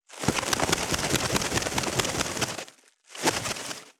660コンビニ袋,ゴミ袋,スーパーの袋,袋,買い出しの音,ゴミ出しの音,袋を運ぶ音,
効果音